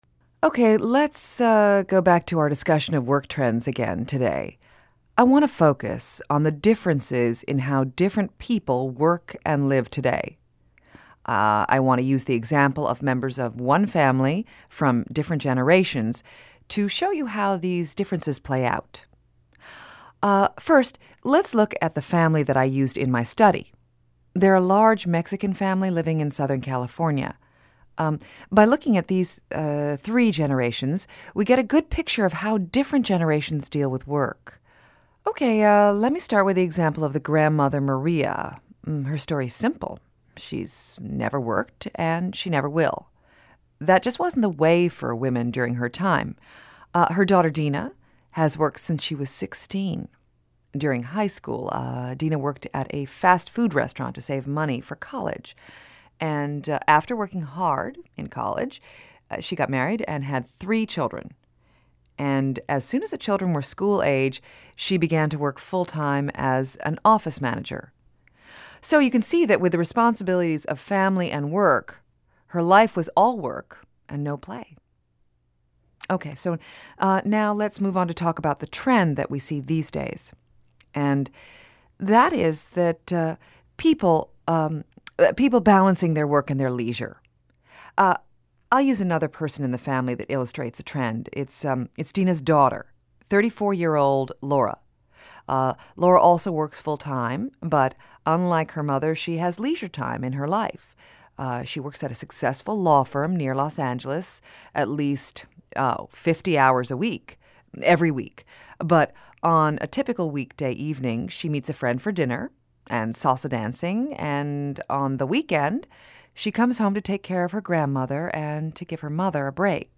lecture on Page 32.
LR2_Ch03_Lecture1.mp3